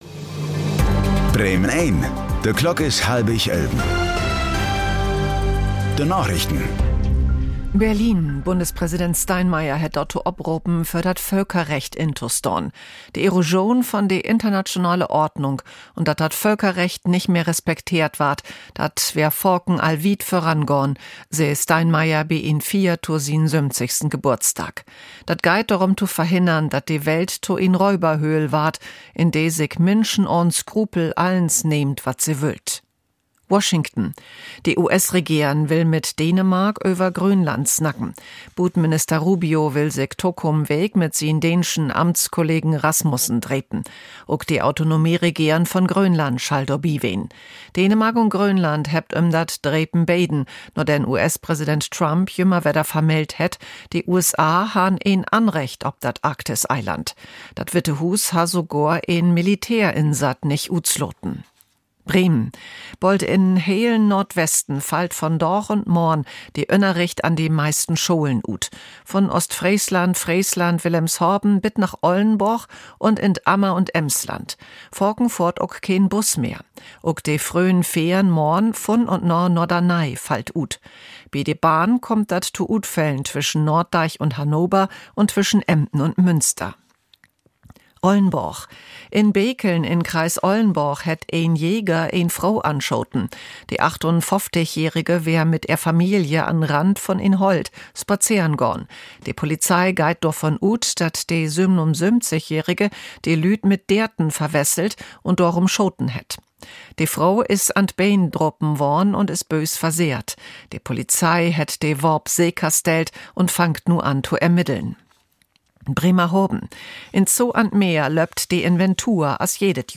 Plattdüütsche Narichten vun'n 8. Januar 2026